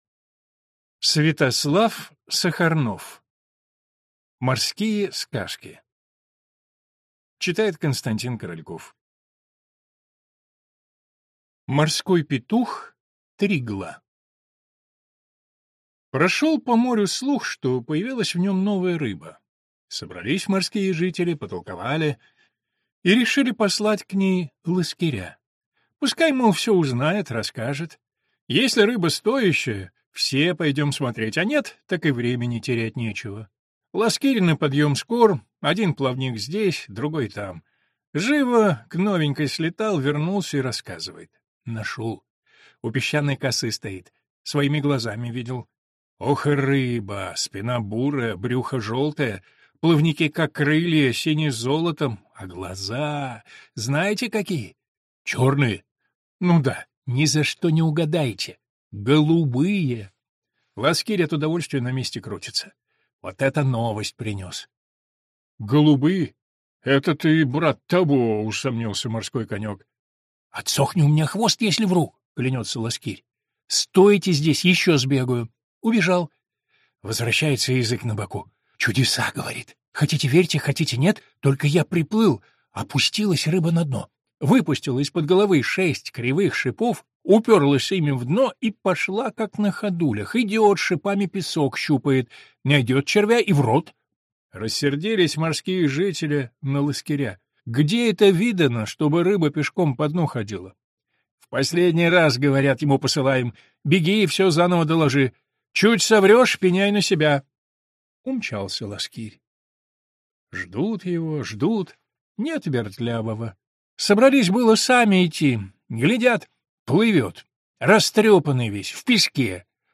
Аудиокнига Морские сказки | Библиотека аудиокниг